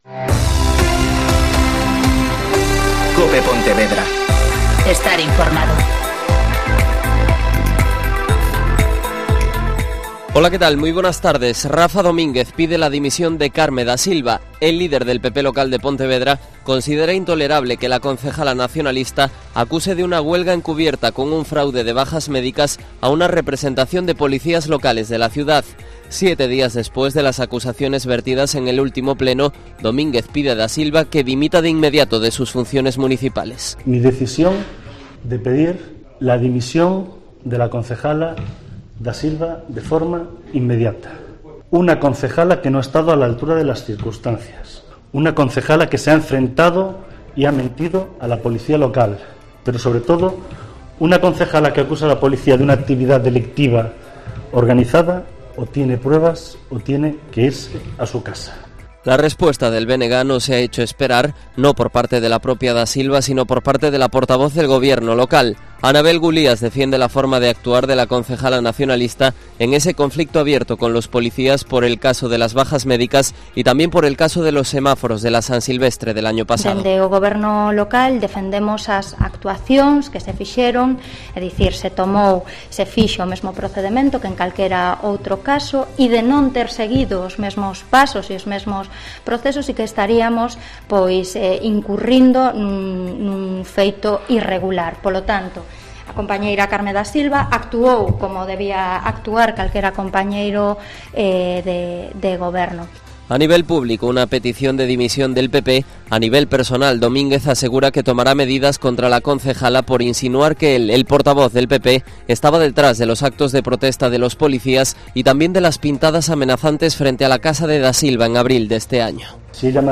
Mediodia COPE POntevedra (Informativo 14:20)